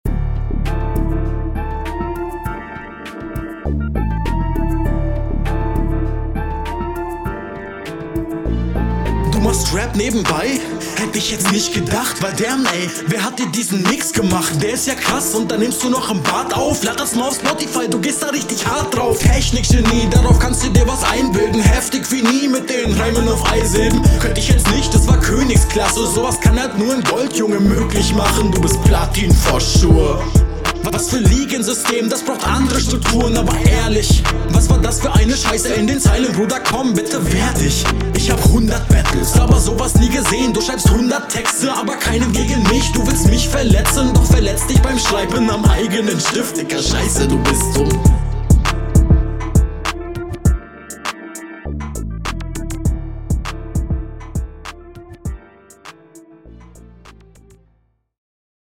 Nice Konter, und kommst auch gut auf dem Beat.